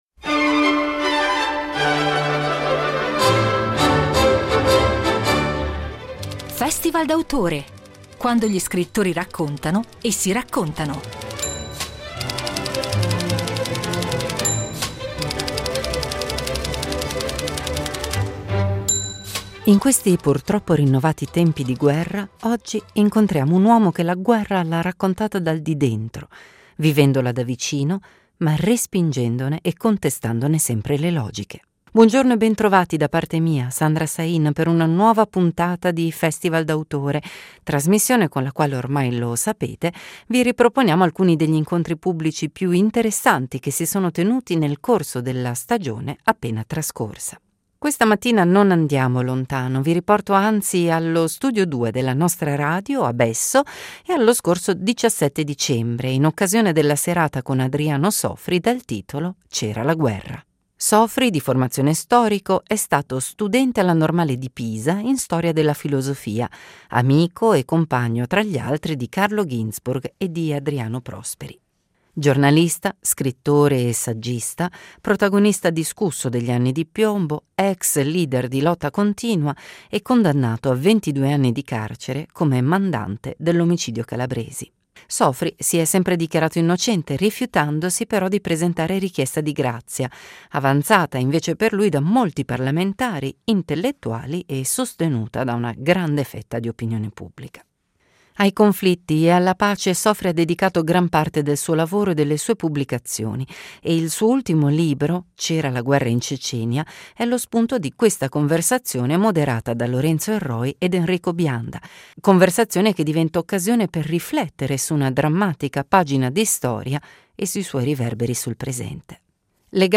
le registrazioni degli incontri pubblici con grandi personalità del mondo dello spettacolo e della cultura
A fine 2024 è stato ospite di una serata evento allo Studio 2 della Radio RSI a Lugano Besso dal titolo C’era la guerra .